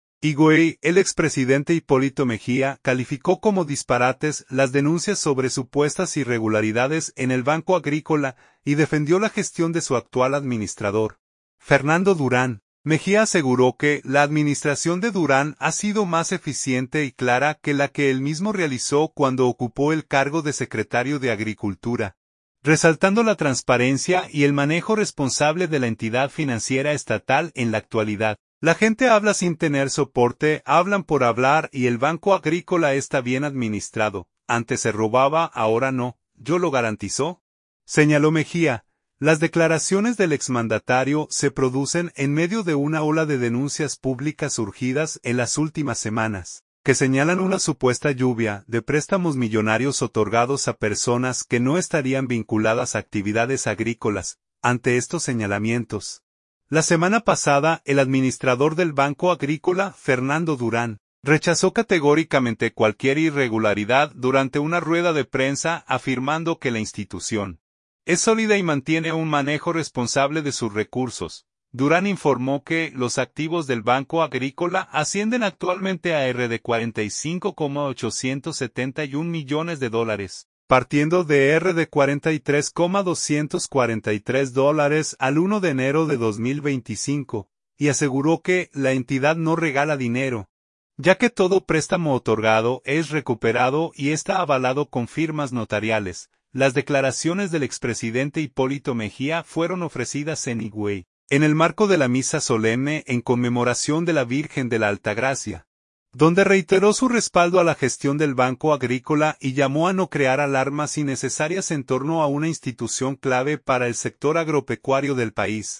Las declaraciones del expresidente Hipólito Mejía fueron ofrecidas en Higüey, en el marco de la misa solemne en conmemoración de la Virgen de la Altagracia, donde reiteró su respaldo a la gestión del Banco Agrícola y llamó a no crear "alarmas innecesarias" en torno a una institución clave para el sector agropecuario del país.